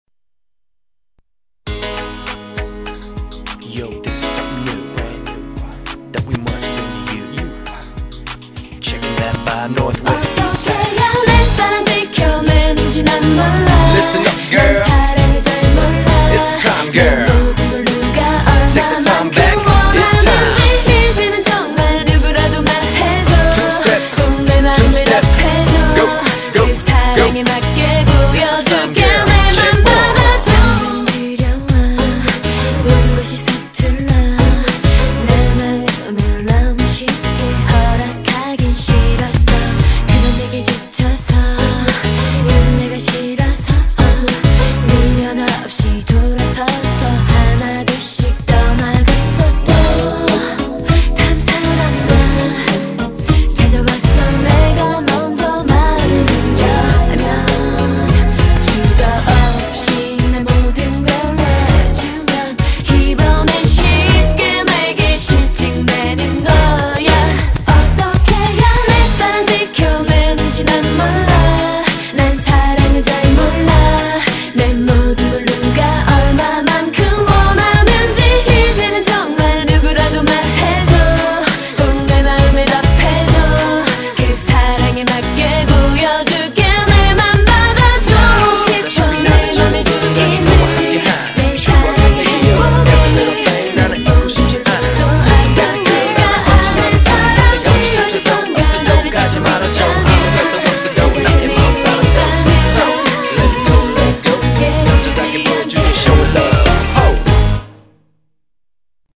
BPM149--1
Audio QualityPerfect (High Quality)